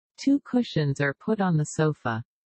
（音声は個人的にパソコンを使って作成したもので、本物の話者ではありません。